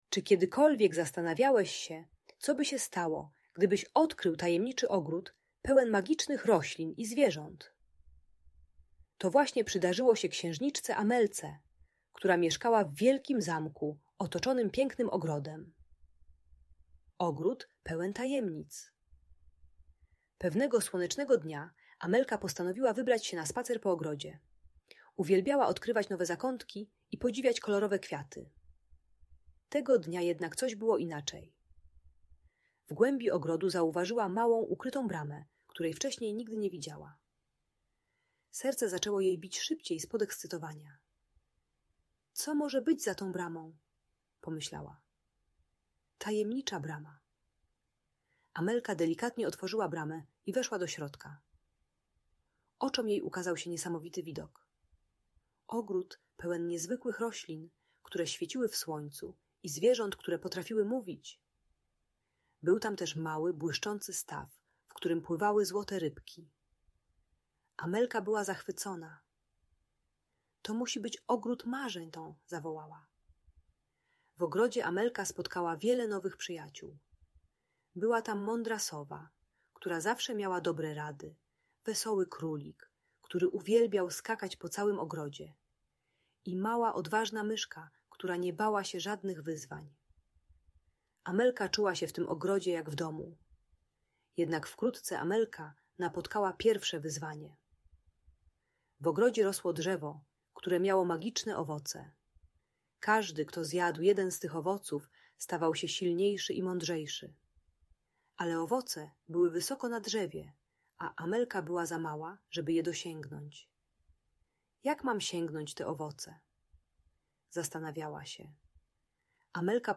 Tajemniczy ogród księżniczki Amelki - Audiobajka